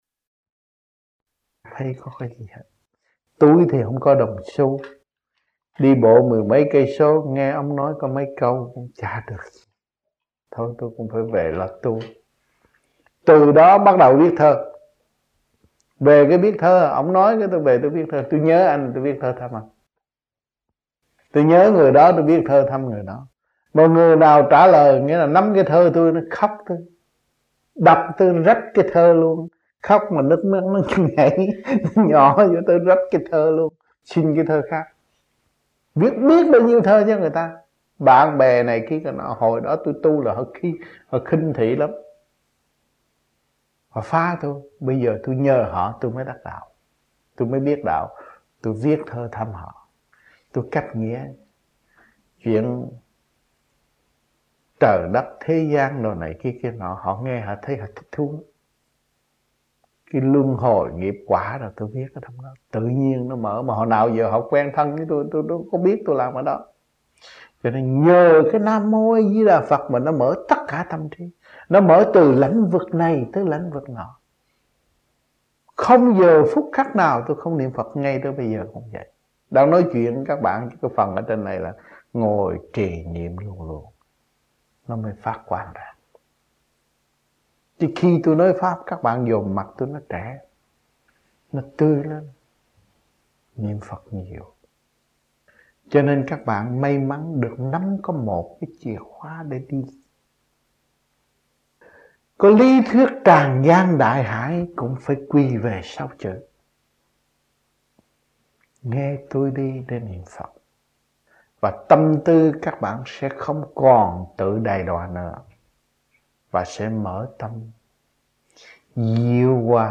Seminar about the Sutras of Buddha A DI DA (VN) 1986 - Khóa Học Kinh A Di Đà (VN)